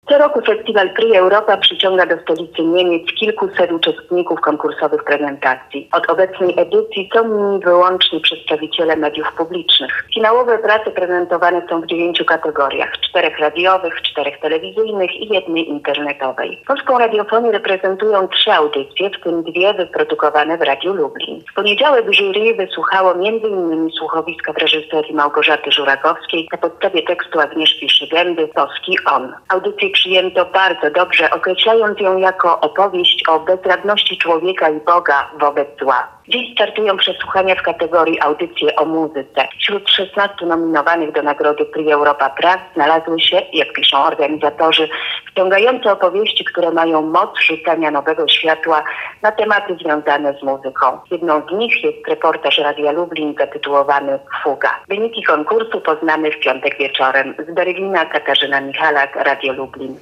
Dziennikarki Radia Lublin w finale Prix Europa. Relacja z Berlina